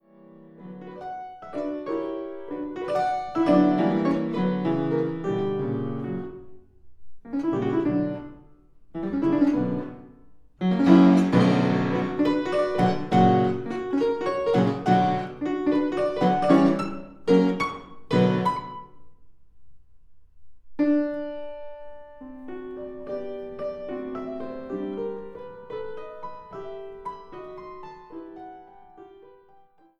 Clavichord